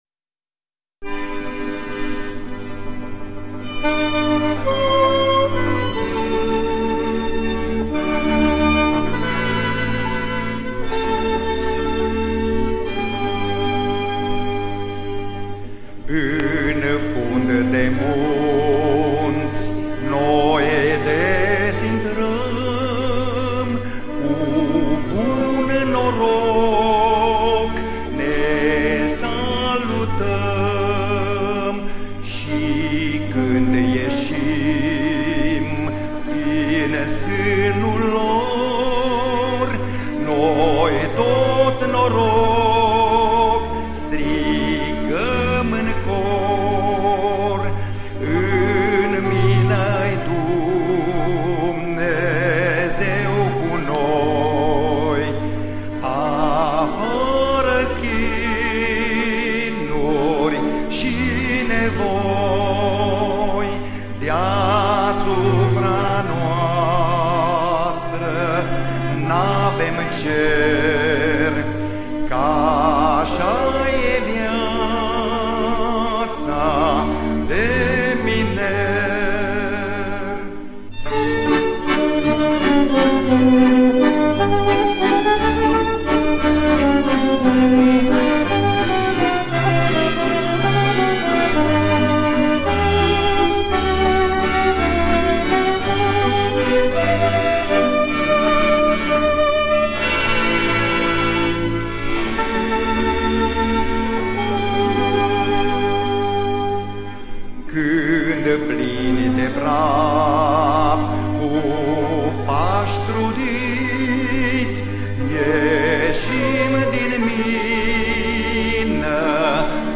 Imnul Minerilor" Bányászhimnusz román nyelven, előadóját nem ismerjük